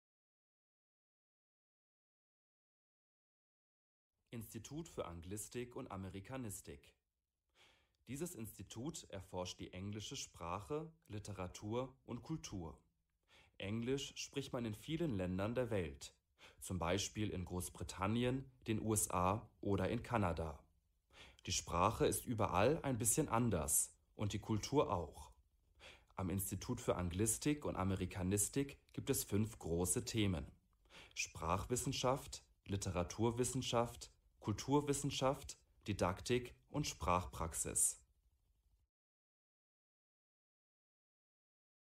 Hörversion der Seite.